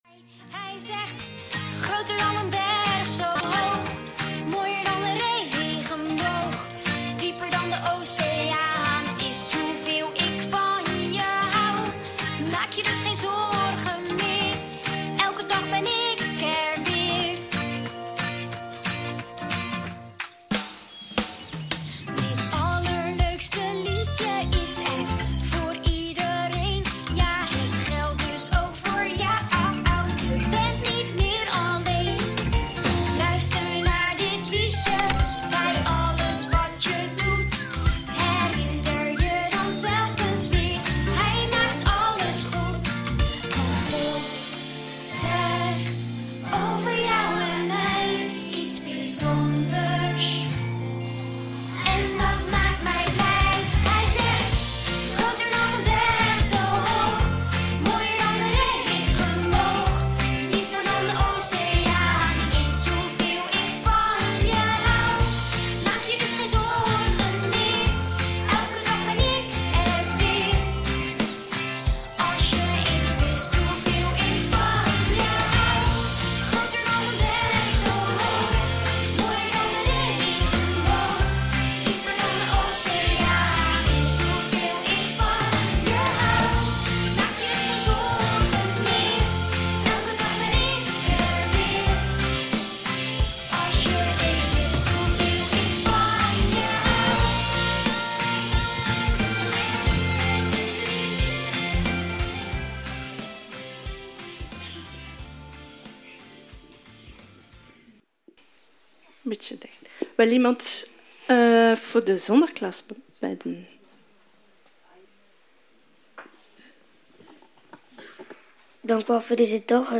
Vandaag starten we een nieuwe reeks preken doorheen Paulus’ brief aan de christenen in Filippi.